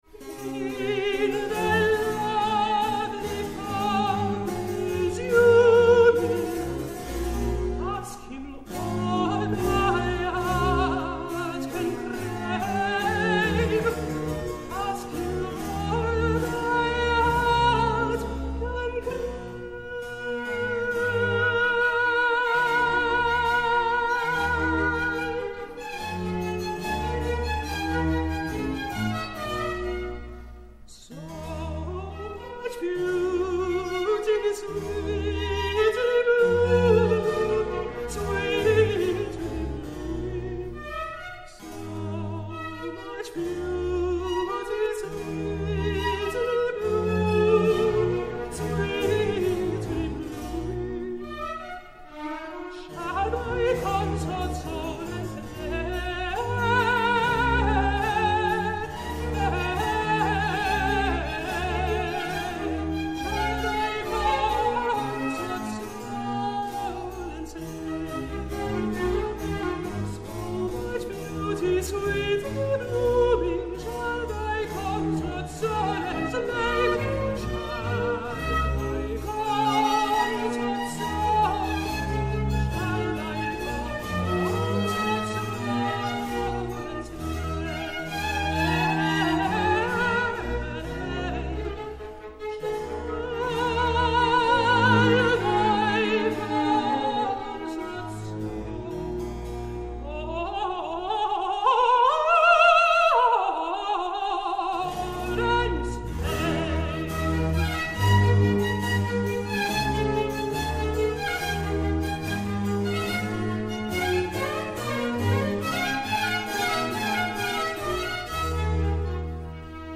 Alte Musik
Konzertmitschnitt vom 10.3.2009